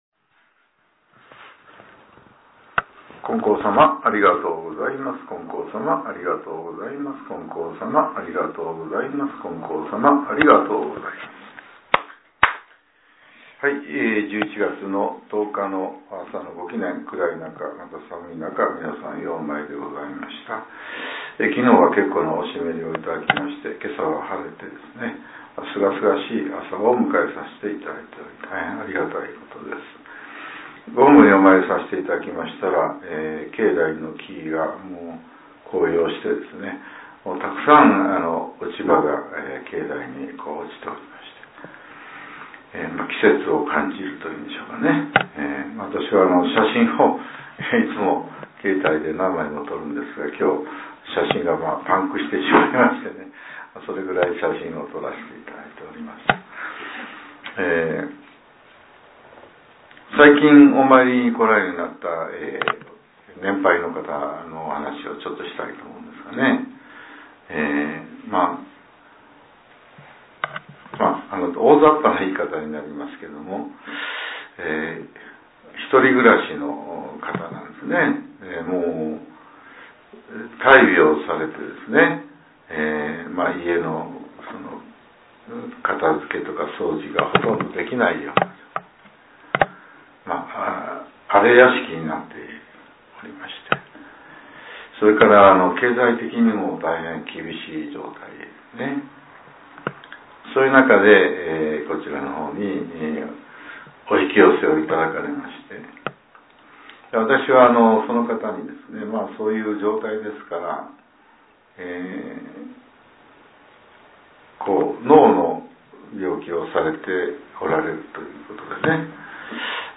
令和７年１１月１０日（朝）のお話が、音声ブログとして更新させれています。